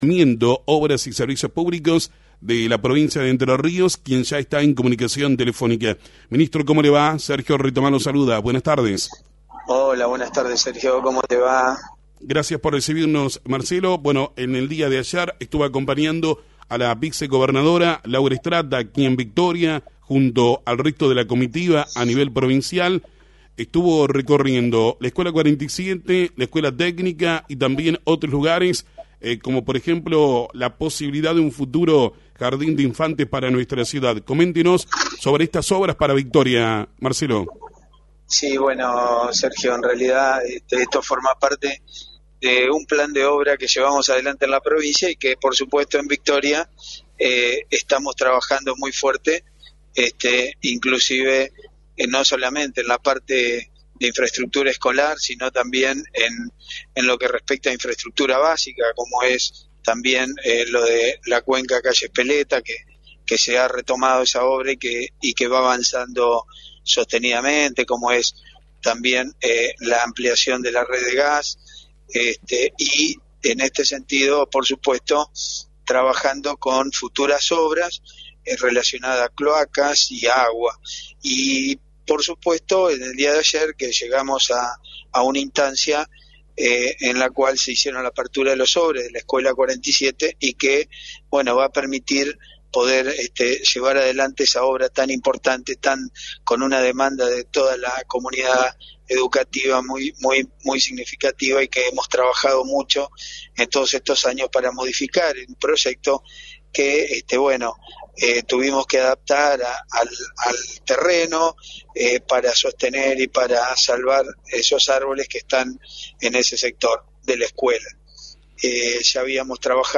El ministro de Planeamiento Arquitecto Richard habló sobre las obras que se llevan a cabo en Victoria – Lt39 Noticias
Ministro de Planeamiento Marcelo Richard en FM 90.3
Entrevistas